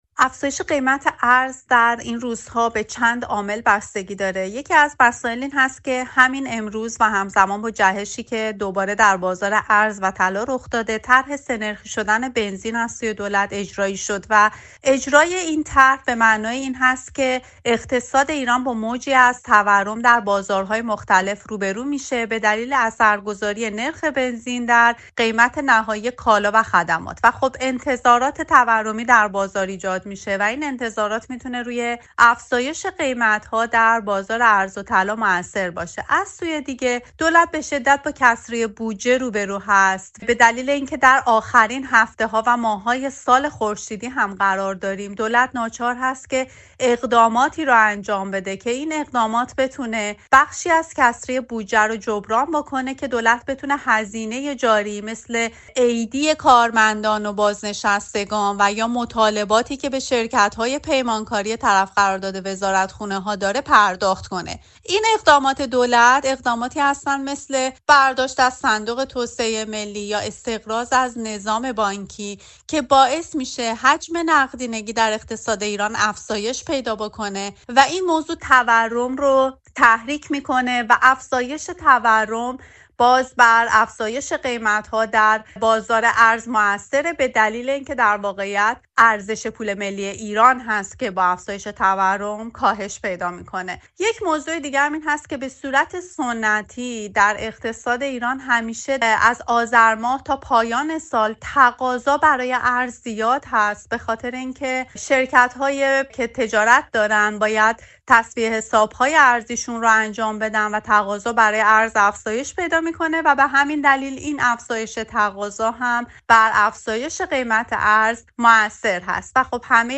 خبرنگار حوزه اقتصاد در فرانسه